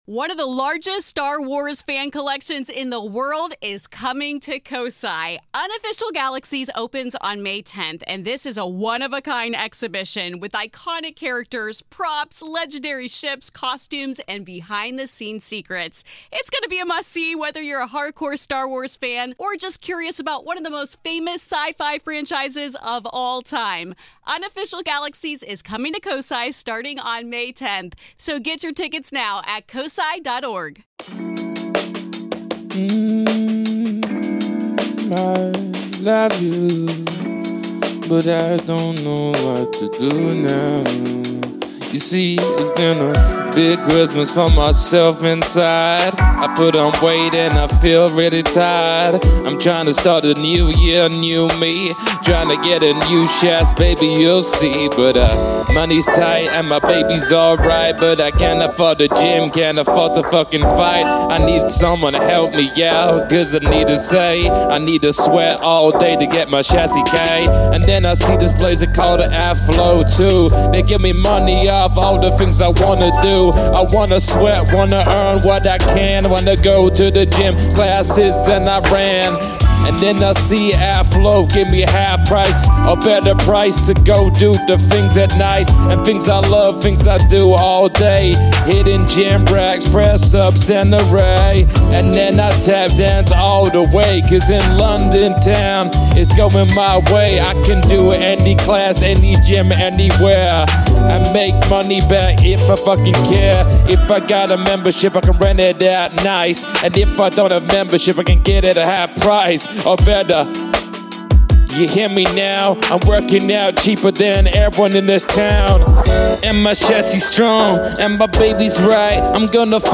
Season 9, Episode 116, Jan 19, 2023, 05:15 AM Facebook Twitter Headliner Embed Embed Code See more options Today we're joined by one of our greatest friends of the show in Sky Sports & Amazon Prime Broadcaster Simon Thomas for somewhat of a 'Dry January' special. Simon has recently passed his 1 year anniversary of being sober and in this deeply personal interview he takes us through the horrendous struggles and relationship he's had with alcohol over the years.